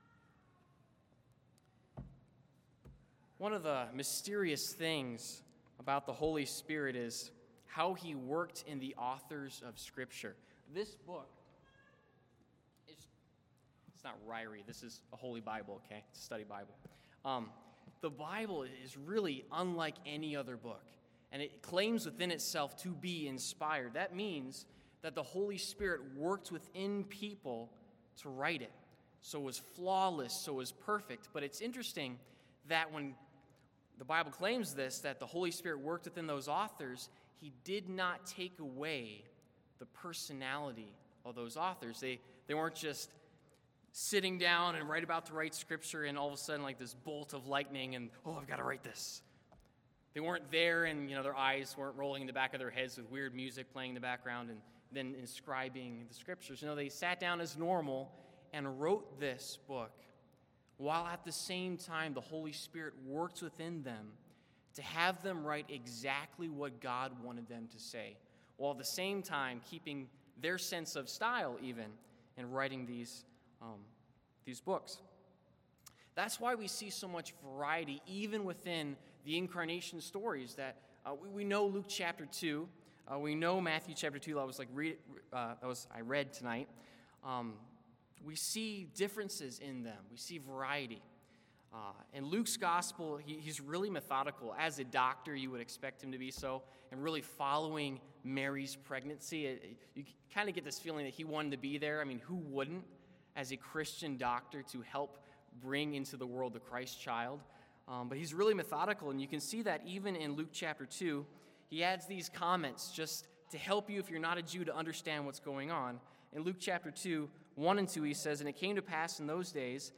Christmas-Eve-Message-W-1.mp3